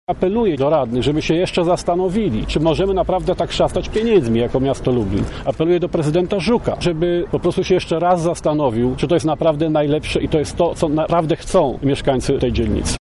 – To skandal – dodaje Palikot